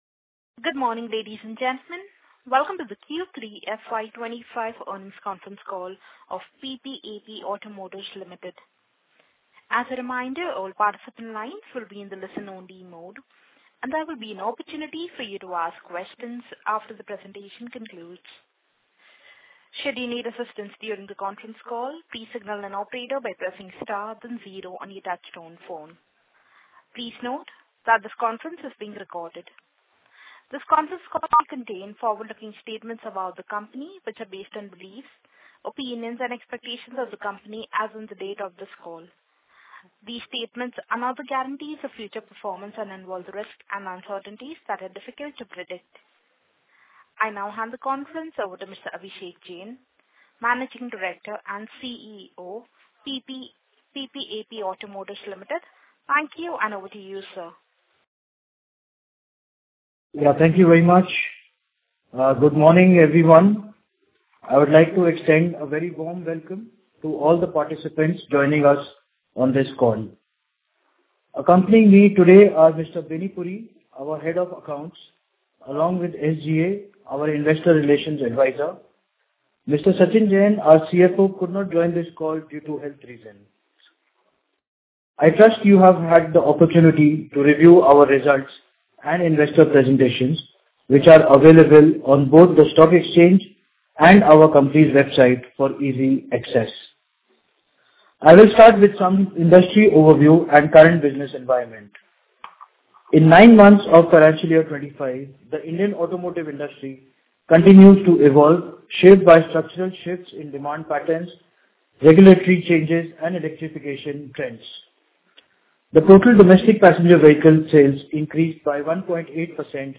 PPAP_Earnings_Call_Audioq3fy25.mp3